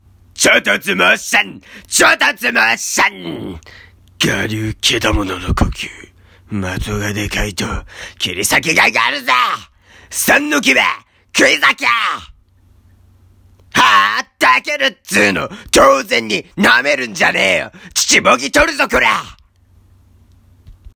【（≧∇≦）】伊之助声真似 nanaRepeat